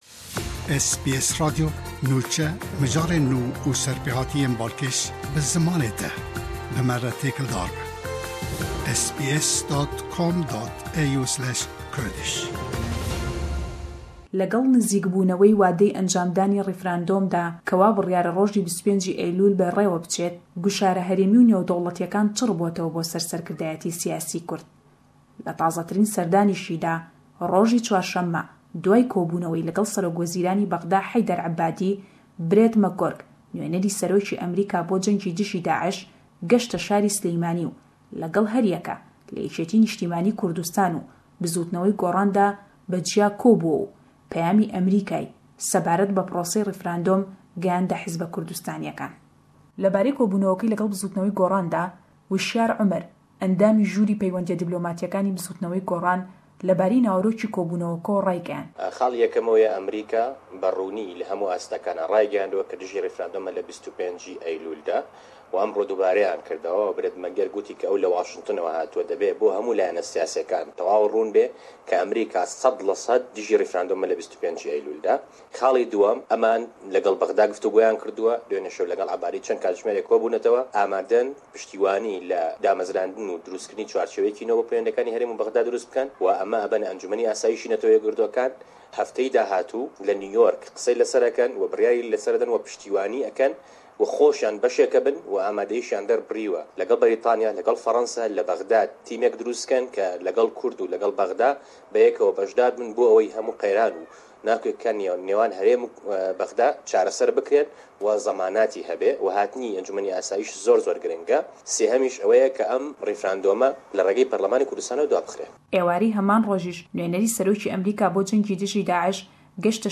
Peyamnêra me